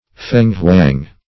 Search Result for " feng-hwang" : The Collaborative International Dictionary of English v.0.48: Feng-hwang \F[^e]ng"-hwang`\, n. [Chin. feng + `huang.] (Chinese Myth.) A pheasantlike bird of rich plumage and graceful form and movement, fabled to appear in the land on the accession of a sage to the throne, or when right principles are about to prevail.